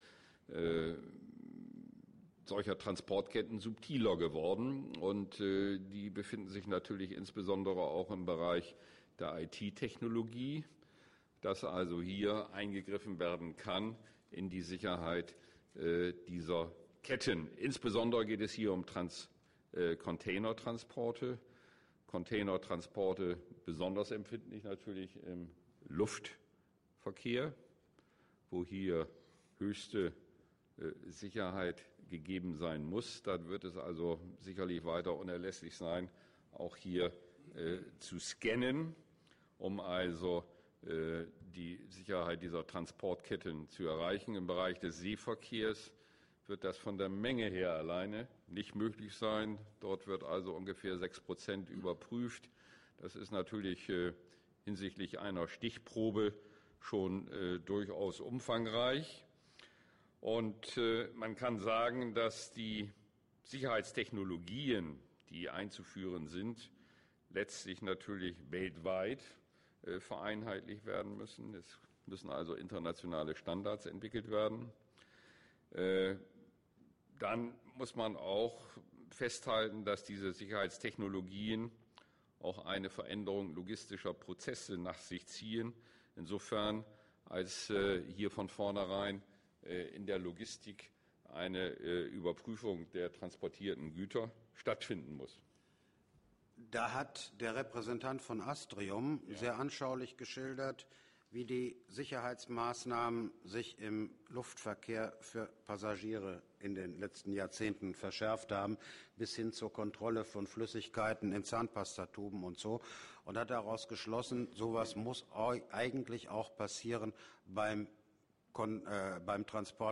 24. Internationales Wirtschafts- und Transportforum in Bremerhaven/“Davos an der Küste“: Bericht von Radio Bremen ( PDF: Europa im Spannungsfeld ) Abschlussdiskussion nachhören ( vgl.